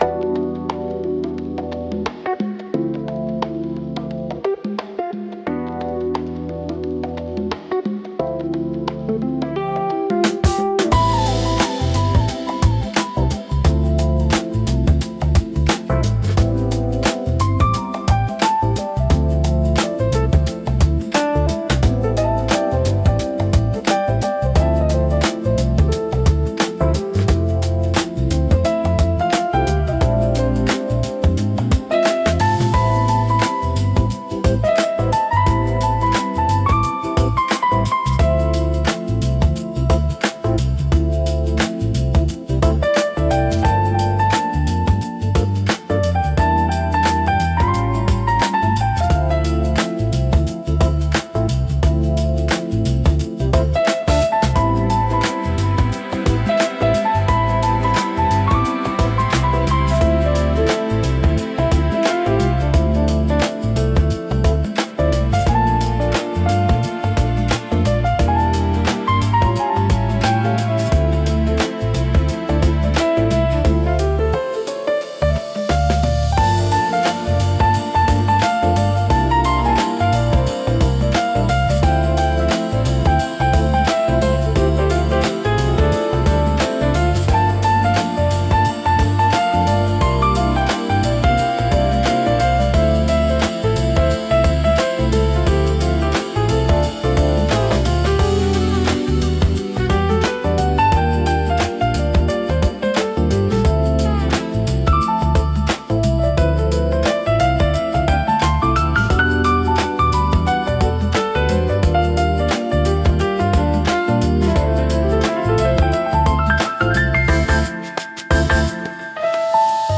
ラウンジは、洗練された落ち着いた雰囲気を持つジャンルで、ゆったりとしたテンポと滑らかなサウンドが特徴です。
ジャズやボサノバ、エレクトロニカの要素が融合し、都会的でリラックスした空間を演出します。
リラックス効果が高く、会話の邪魔をせず心地よい背景音として居心地の良さを高めます。